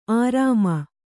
♪ ārāma